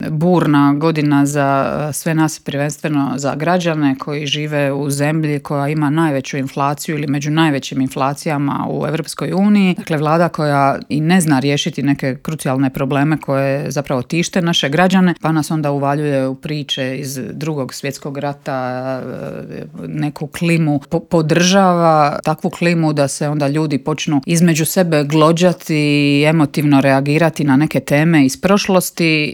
ZAGREB - U Intervjuu Media servisa ugostili smo saborsku zastupnicu i splitsku gradsku vijećnicu Centra Marijanu Puljak.